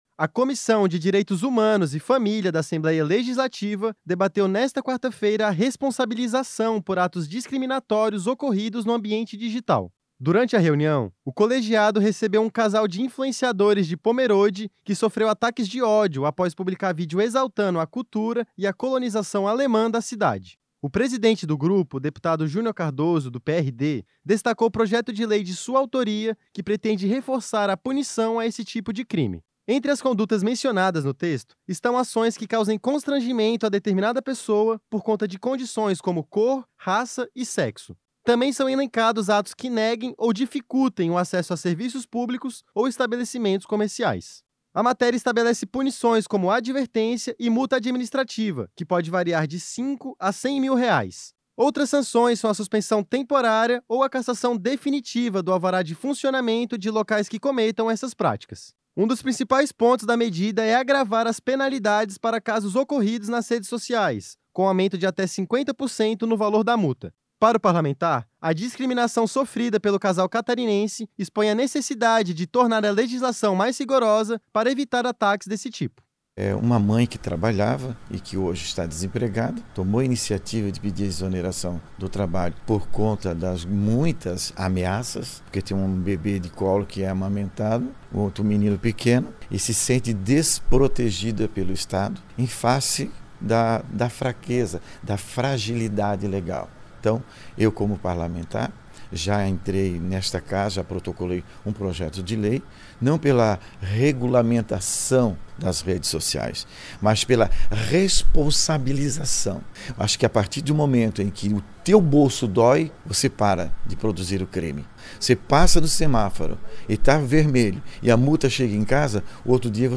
Entrevista com:
- deputado Junior Cardoso (PRD), presidente da Comissão de Direitos Humanos e Família.